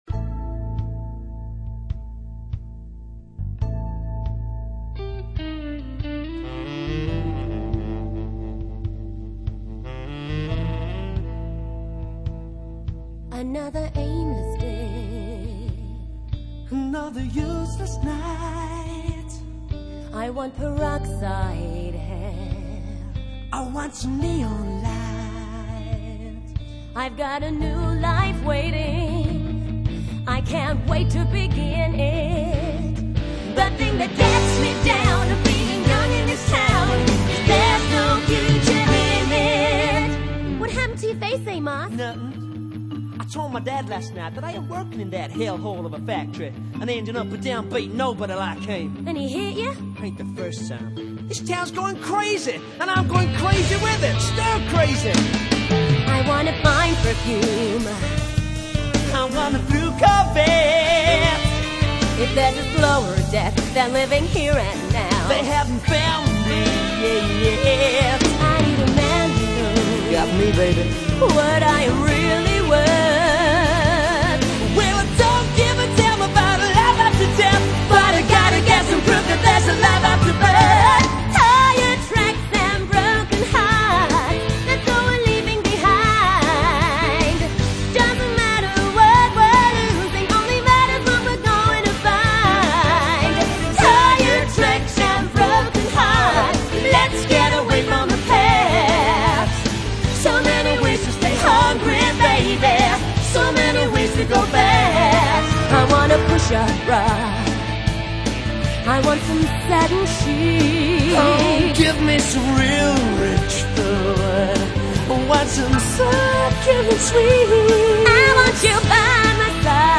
這是 Amos 與他的女朋友 Candy 所合唱的歌。
曲風比較樸實